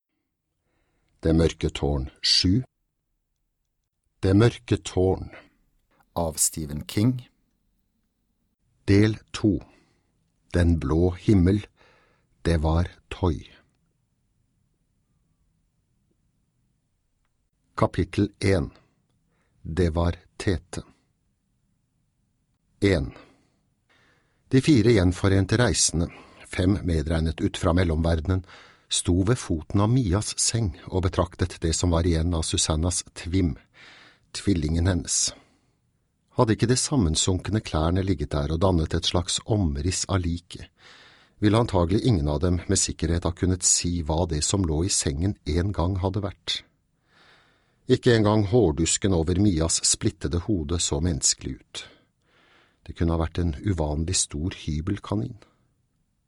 Det mørke tårn VII - Del 2 - Den blå himmel Devar-Toi (lydbok) av Stephen King